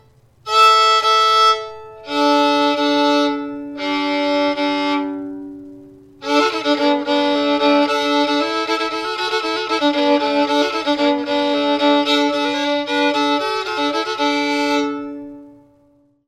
New Handmade Violin / Fiddle Outfit with case & bow - $399.00
I would classify this one as loud in volume, with a bright and clear tone quality.